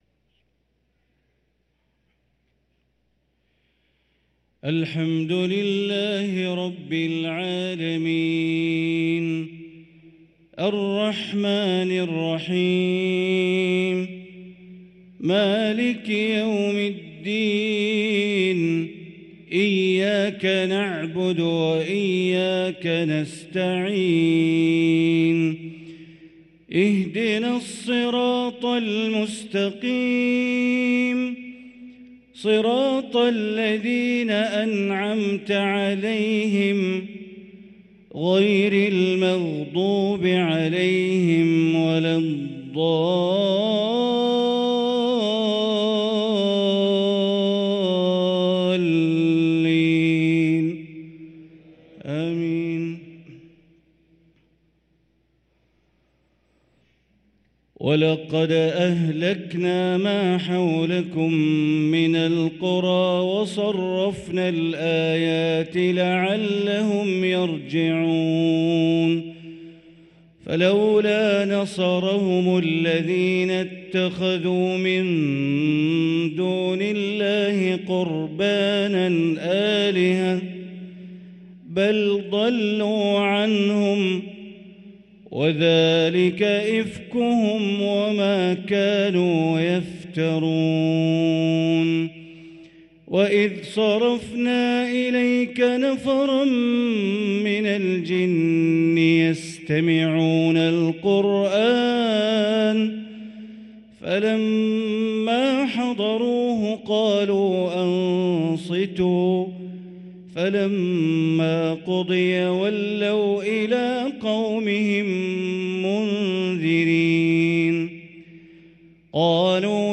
صلاة العشاء للقارئ بندر بليلة 15 شعبان 1444 هـ
تِلَاوَات الْحَرَمَيْن .